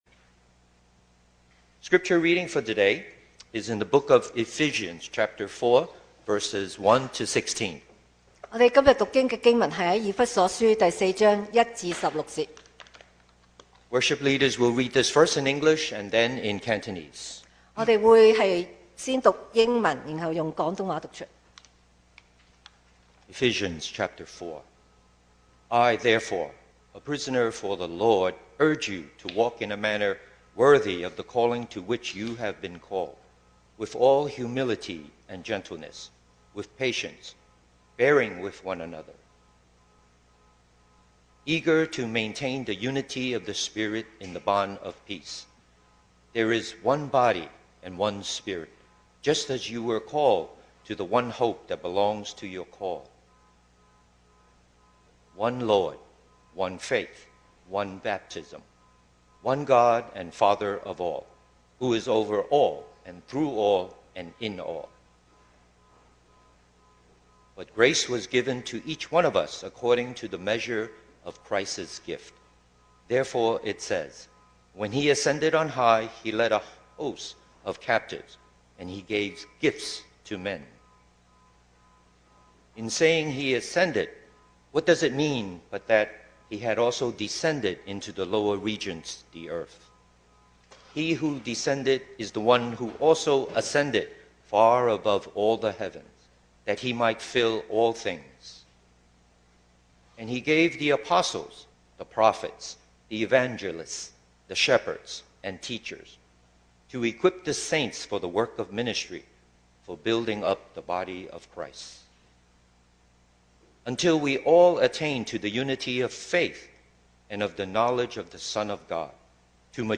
2025 sermon audios 2025年講道重溫
Service Type: Sunday Morning